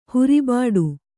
♪ huribāḍu